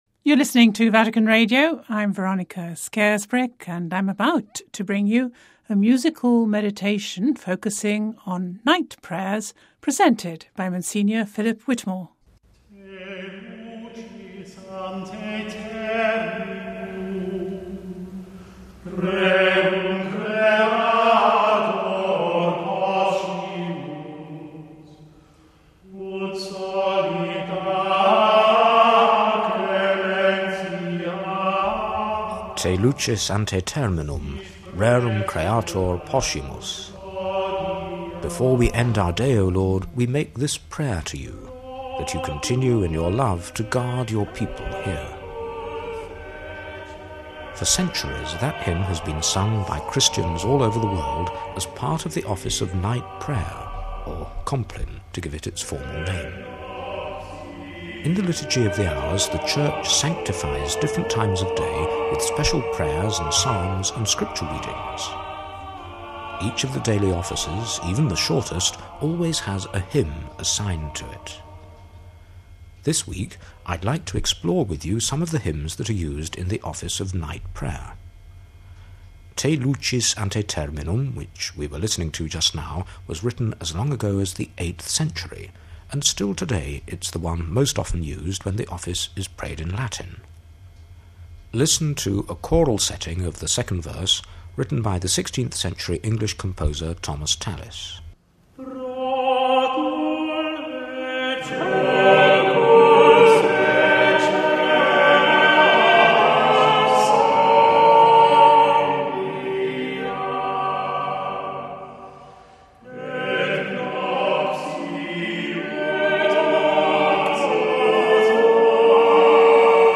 musical meditation